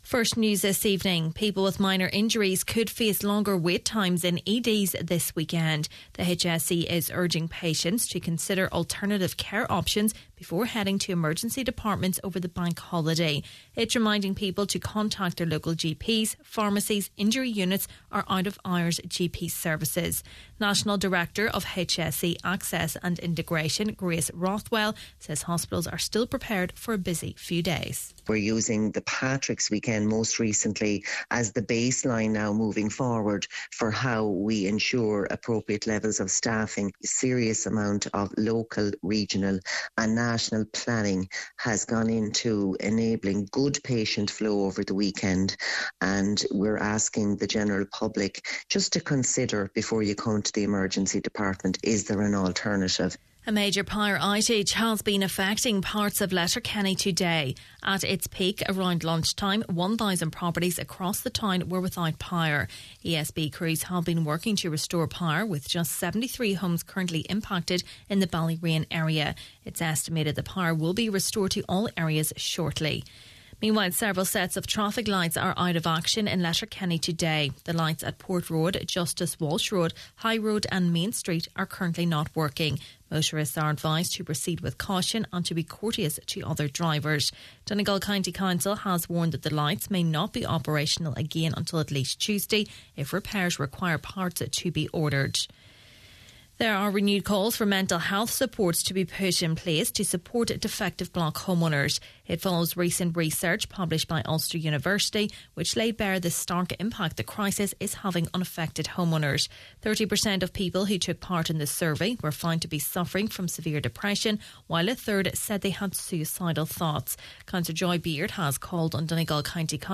Main Evening News, Sport, Nuacht and Obituaries – Friday, April 18th